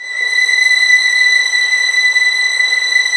Index of /90_sSampleCDs/Roland L-CD702/VOL-1/STR_Vlns 6 mf-f/STR_Vls6 mf%f St